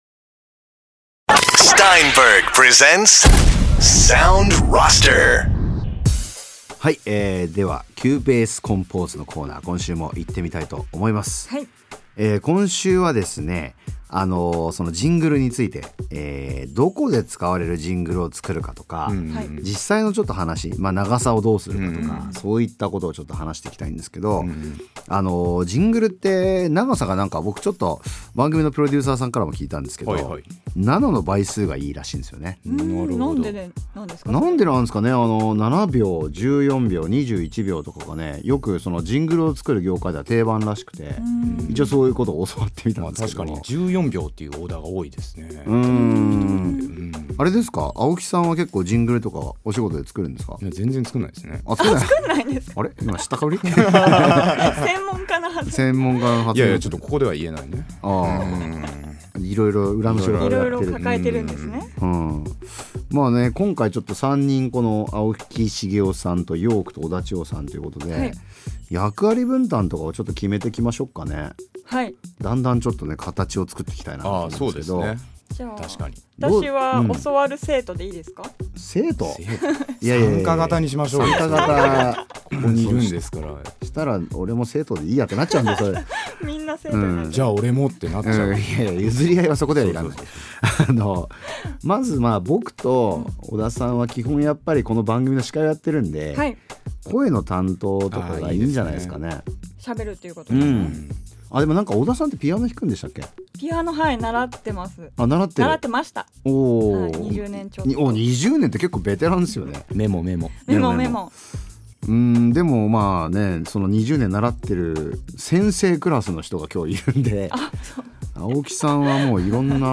Steinberg が提供するラジオ番組「Sound Roster」。